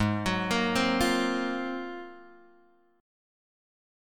G#13 chord {4 3 4 3 6 6} chord